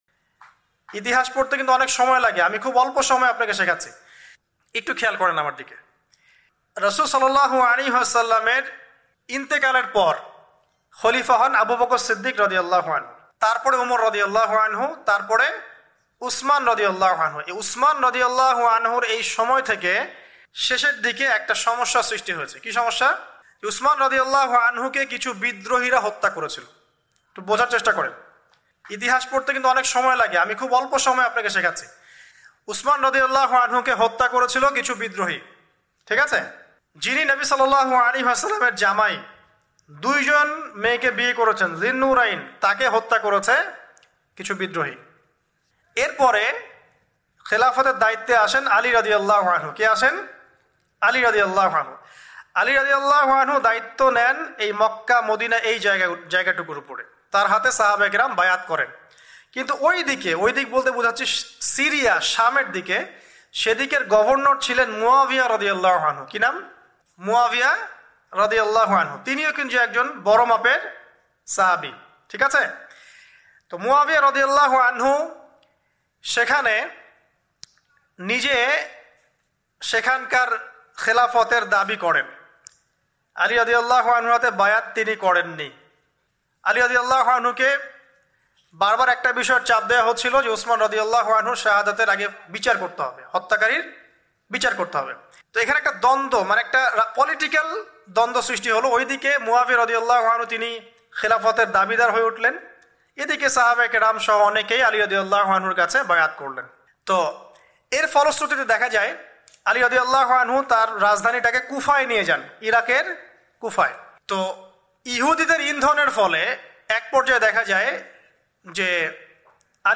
waz